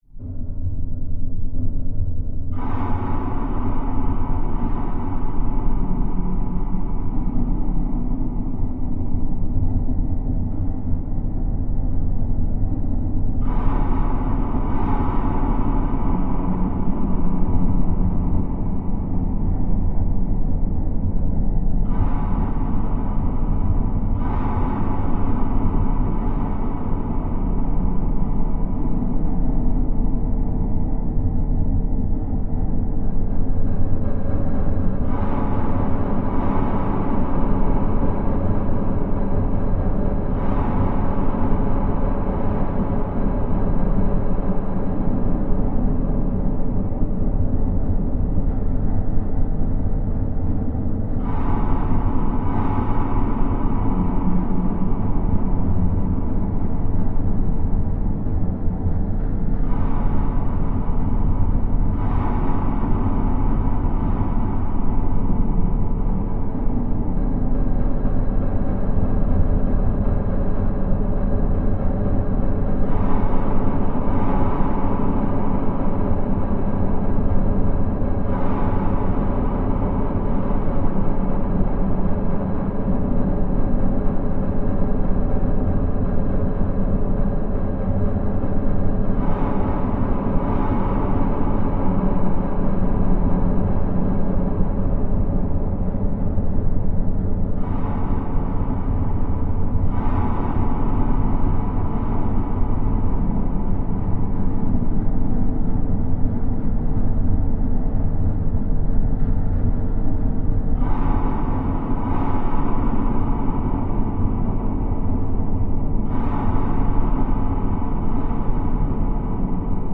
Steady Low Airy Passageway Passageway, Airy